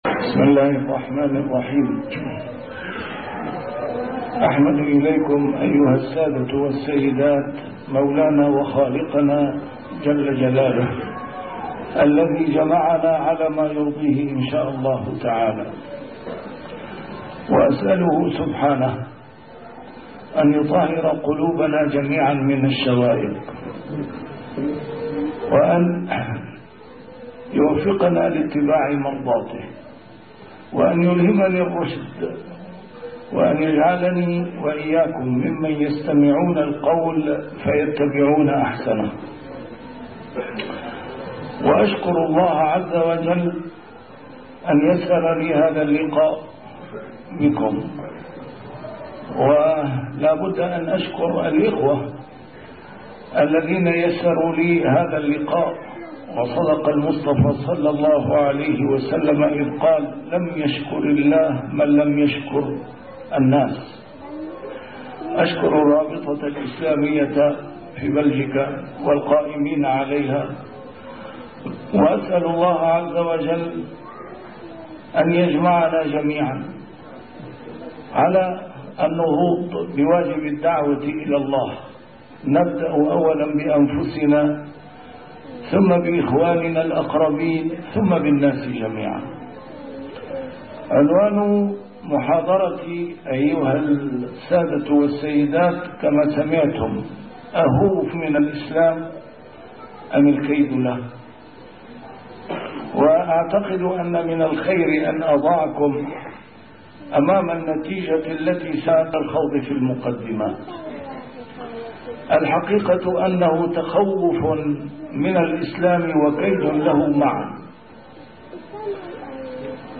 نسيم الشام › A MARTYR SCHOLAR: IMAM MUHAMMAD SAEED RAMADAN AL-BOUTI - الدروس العلمية - محاضرات متفرقة في مناسبات مختلفة - أهوَ الخوف من الإسلام أم الكيد له